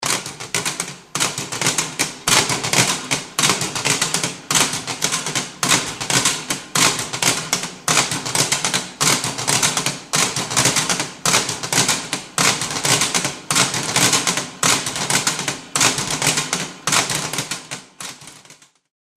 Electrical Shocks / Bursts 3; Repetitive Electrical Zaps; Mid Frequency, Metallic, Reverberant, Medium Perspective. Arc, Spark.